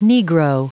Prononciation du mot negro en anglais (fichier audio)
Prononciation du mot : negro